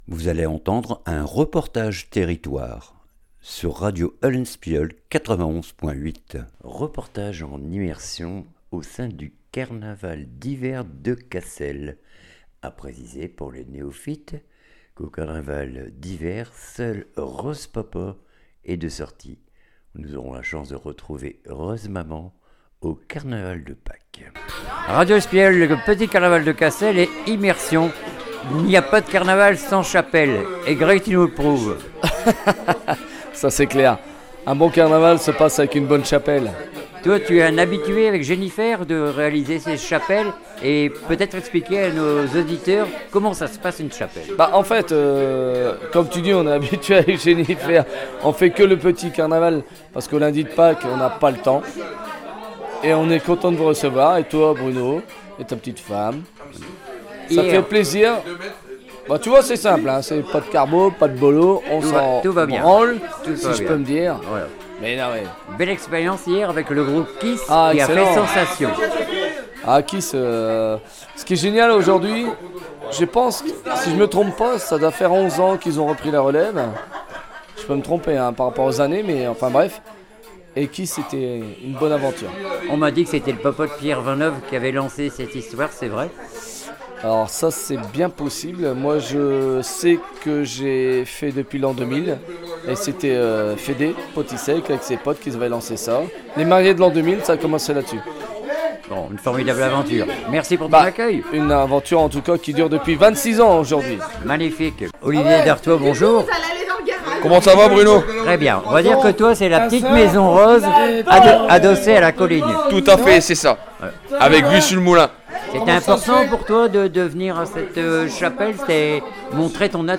REPORTAGE TERRITOIRE CARNAVAL D HIVER CASSEL 2026
IMMERSION AU SEIN DU CARNAVAL D HIVER A CASSEL 2026, DES TEMOIGNAGES; DES ACTEURS, DES BENEVOLES......